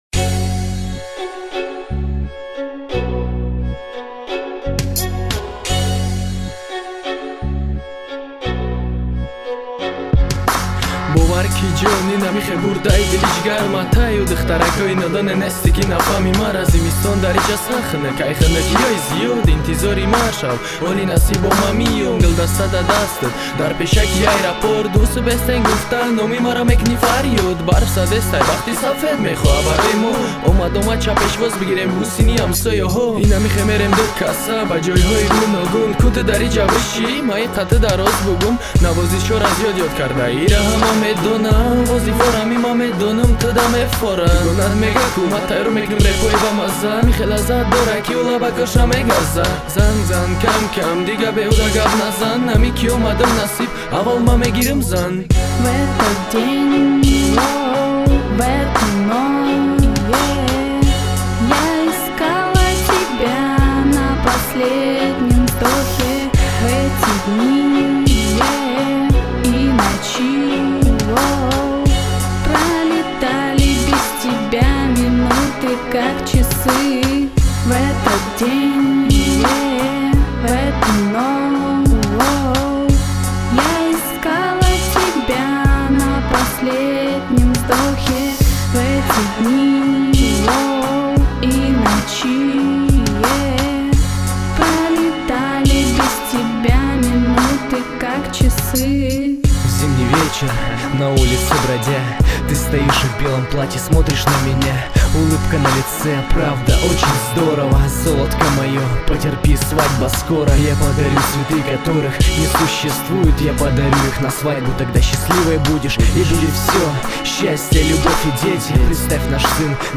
Главная » Музыка » Rap,hip-hop